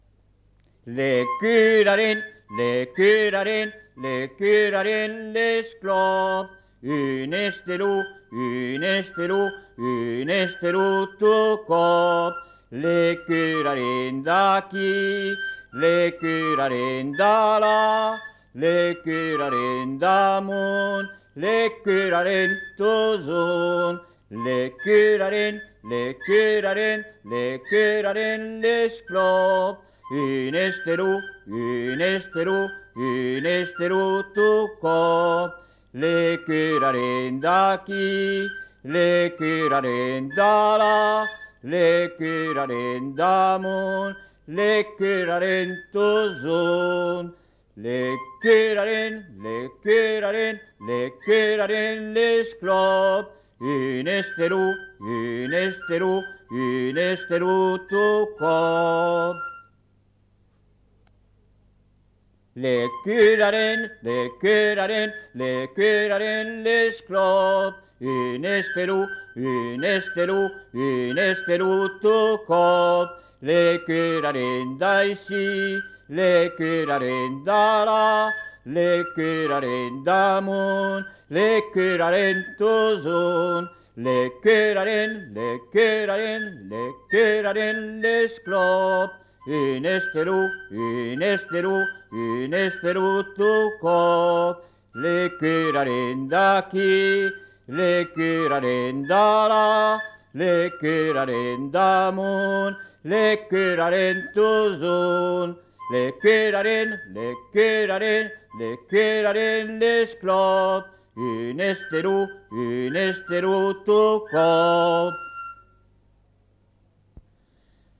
Lieu : La Tourette-Cabardès
Genre : chanson-musique
Type de voix : voix d'homme
Production du son : chanté
Instrument de musique : fifre
Danse : varsovienne